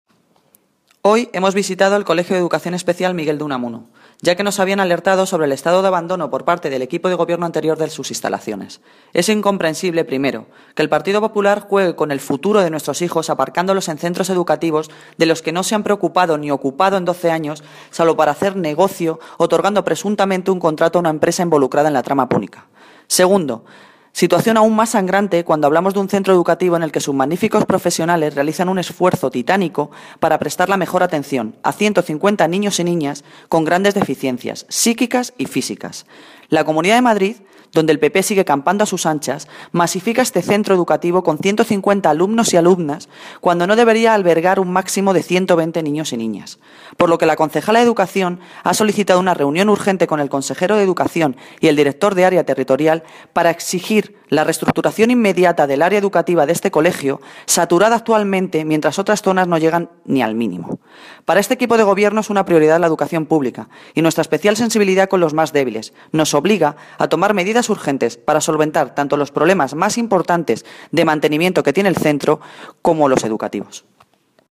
Audio - Noelia Posse (Concejala de Medio Ambiente y Servicios Generales) Sobre centro educación especial